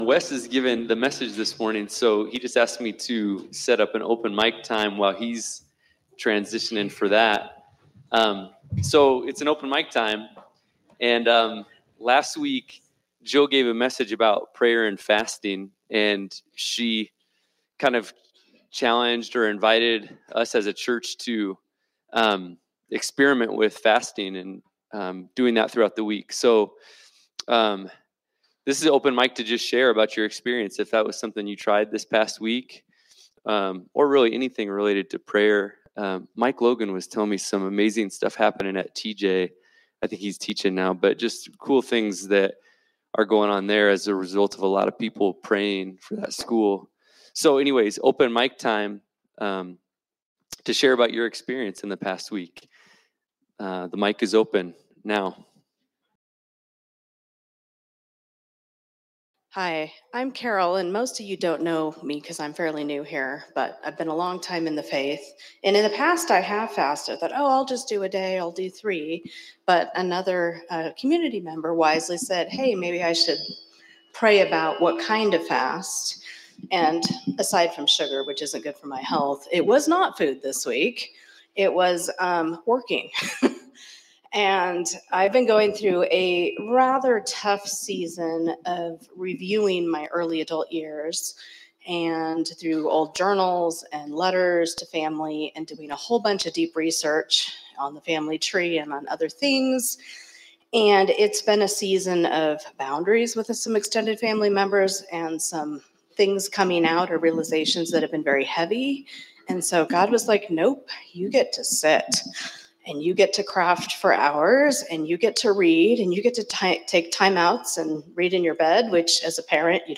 Sermon from Celebration Community Church on November 9, 2025